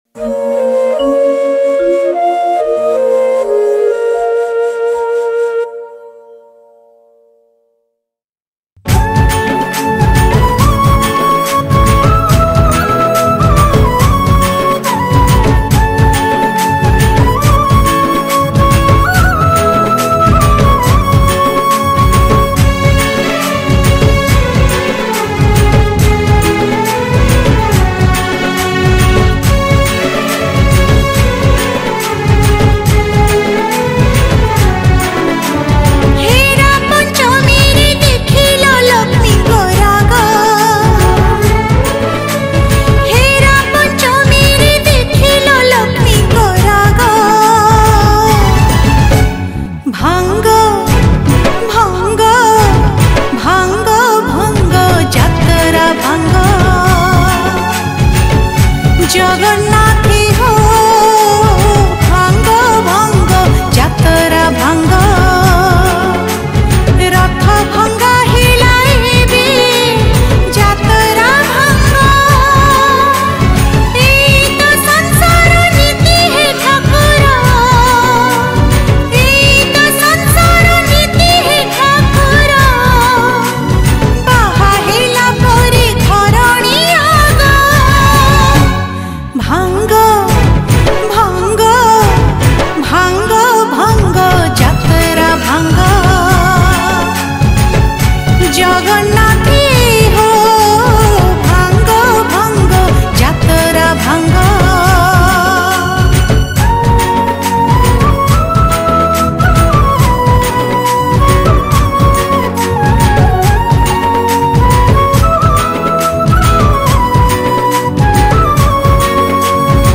Ratha Yatra Odia Bhajan 2023 Songs Download
STUDIO :- SR STUDIO AND BM STUDIO, CUTTACK